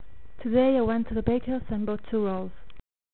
The volume is quite low, I hope you can hear something.
Is it just me or does my voice sound different when I speak in different languages? o_O